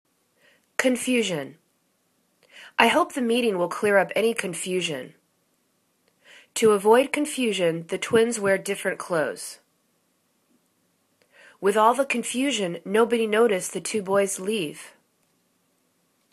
con.fu.sion     /kən'fyu:jən/    n